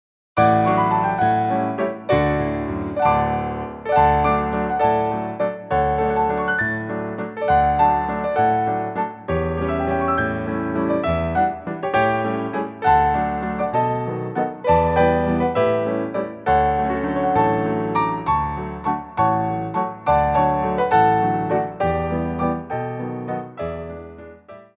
Grands Battements en Clôche
3/4 (8x8)